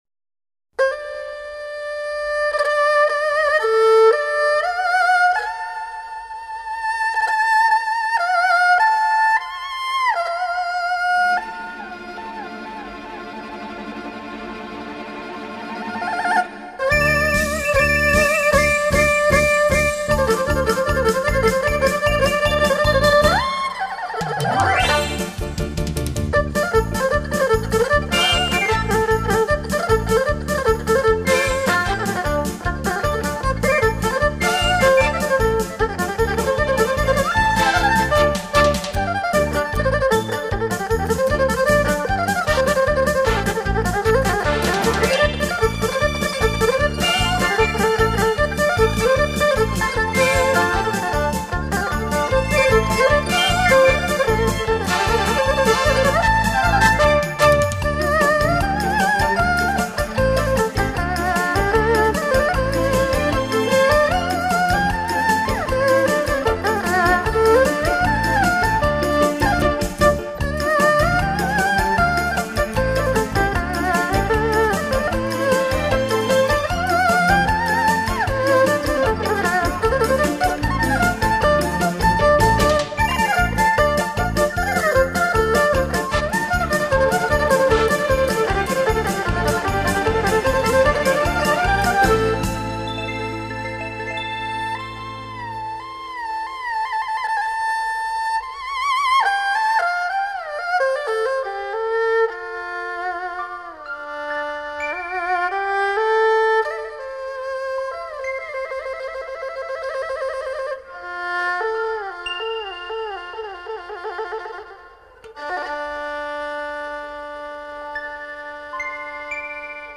二胡演奏